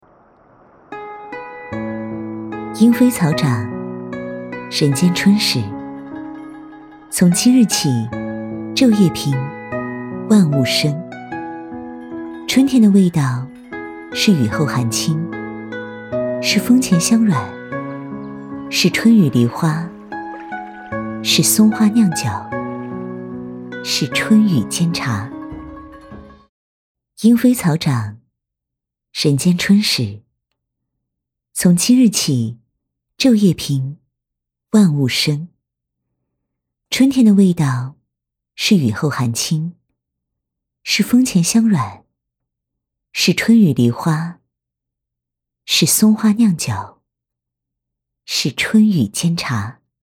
v385-【朗诵】春分 自然温柔
女385温柔知性配音 v385
v385--朗诵-春分-自然温柔.mp3